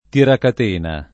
tiracatena [ tirakat % na ] s. m.; inv.